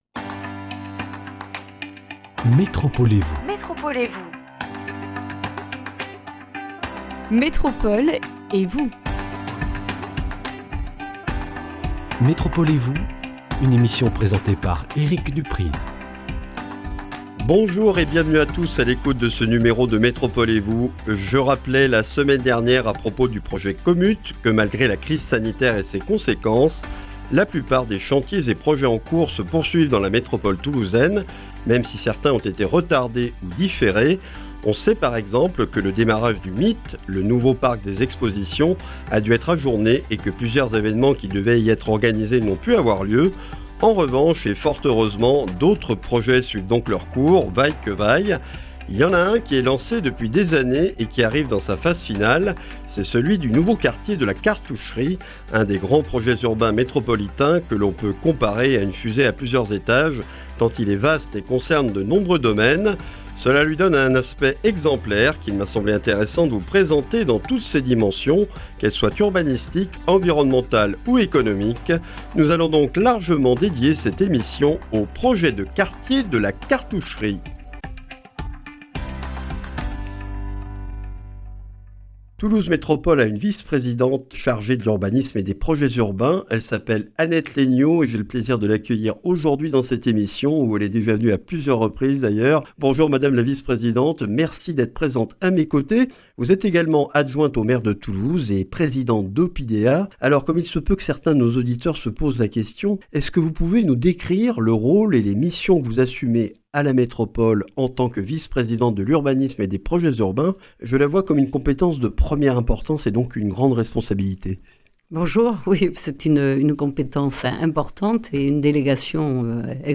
REDIFFUSION : Le nouveau quartier de La Cartoucherie est un des grands projets urbains métropolitains des dernières années. Annette Laigneau, adjointe au maire de Toulouse, vice-présidente de Toulouse Métropole chargée de l’Urbanisme et des Projets urbains, nous en présente toutes les dimensions, urbanistiques, environnementales, économiques et sociales.